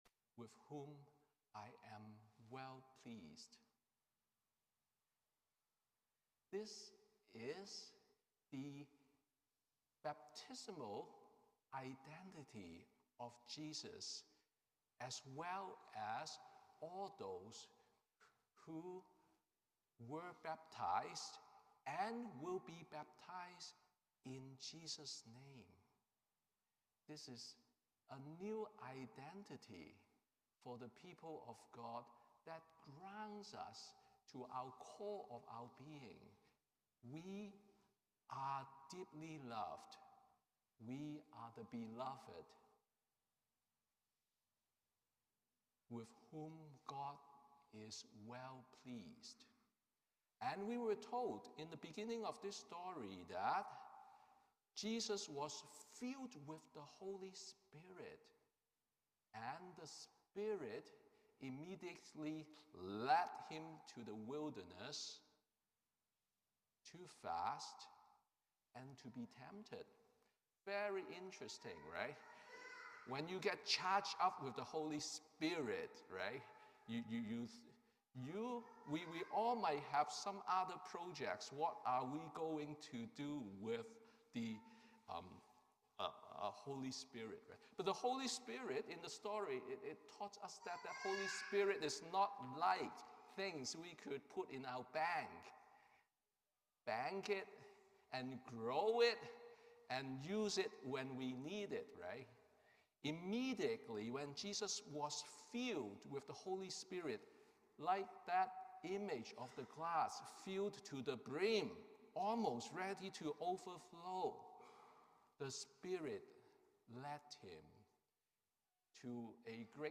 Sermon on First Sunday in Lent